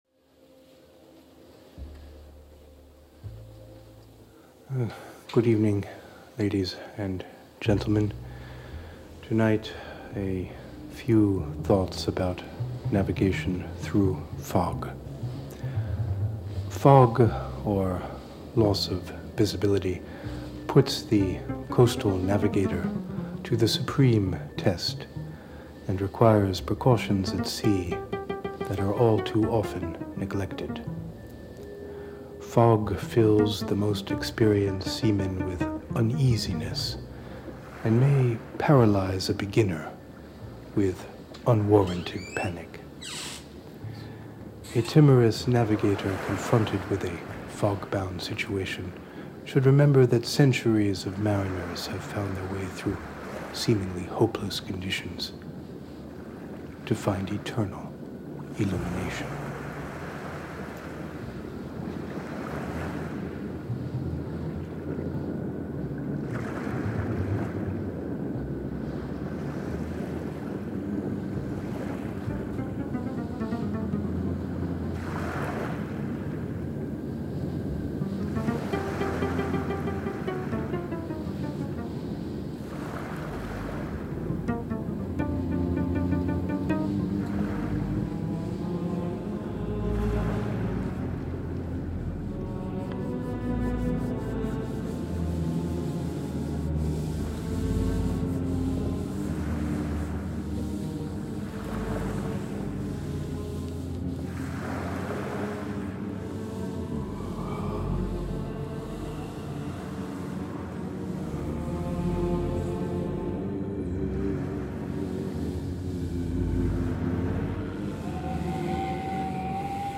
____ A radio navigation commissioned by Sound Culture 1996 in San Francisco
Recorded in the sea-crow media studio on Nantucket Island, over the course of several foggy days in late winter.